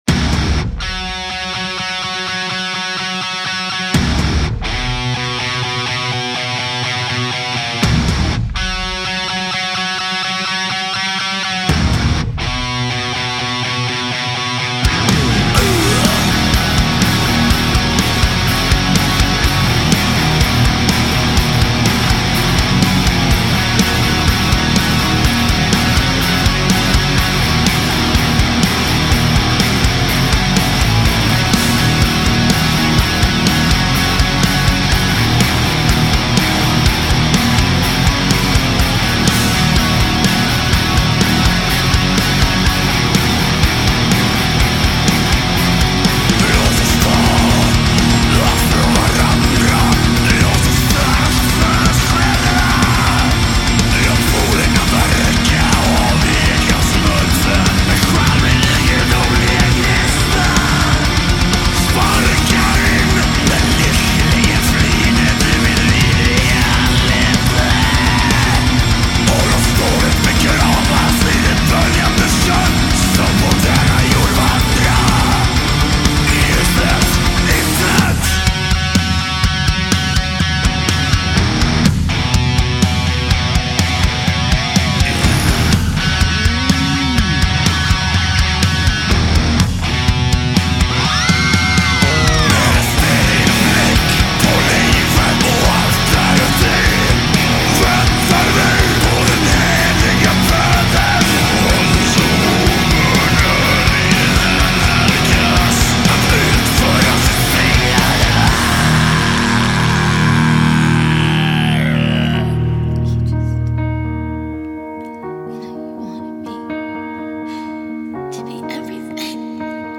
=DEPRESSIVE BLACK METAL=